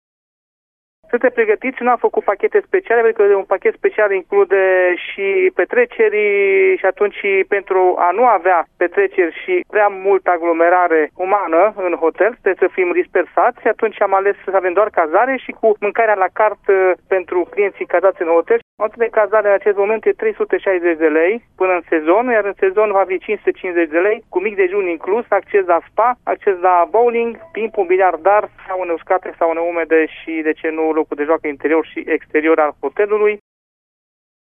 Managerul unui hotel din Poiana Braşov